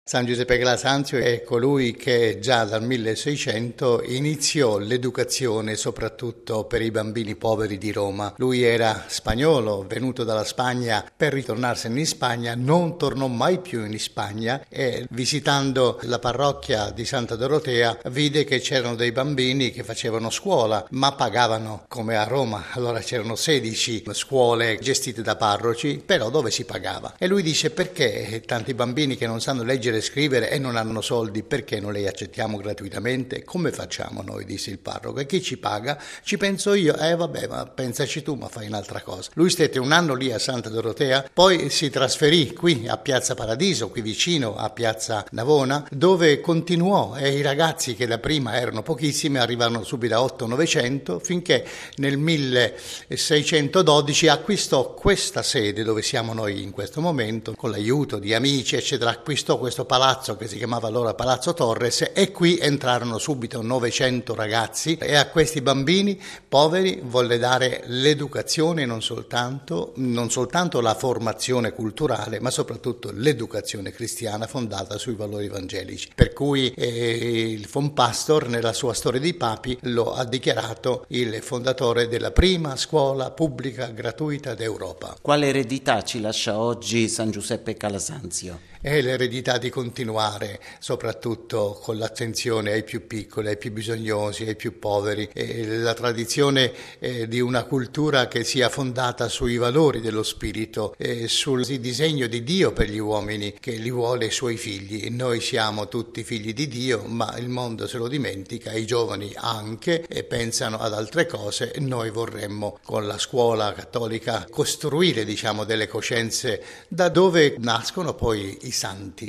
San Giuseppe Calasanzio è stato un grande apostolo della gioventù e si è adoperato per l’educazione dei ragazzi soprattutto poveri. Ascoltiamo in proposito il padre scolopio